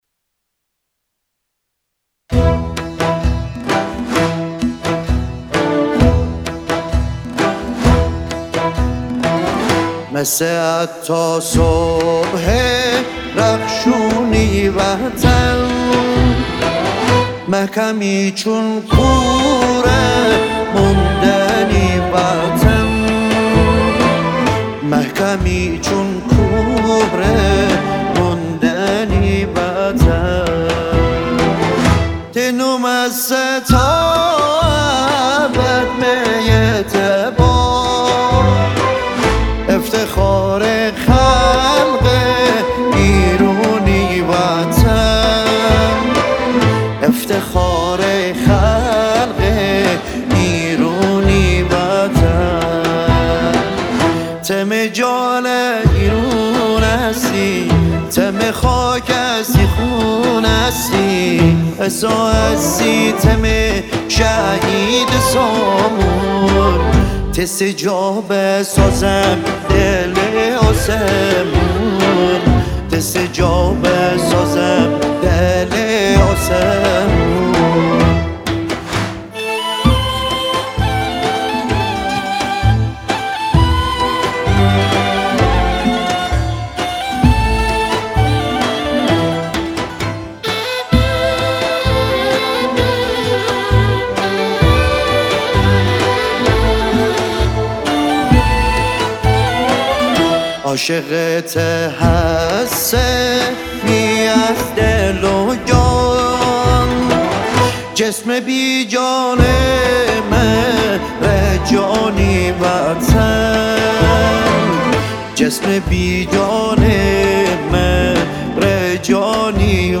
سنتی
با سبک سنتی مازندرانی